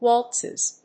/ˈwɔltsʌz(米国英語), ˈwɔ:ltsʌz(英国英語)/